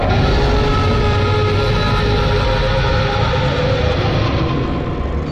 Dragon Roar [1]
dragon-roar-1_rgUQSld.mp3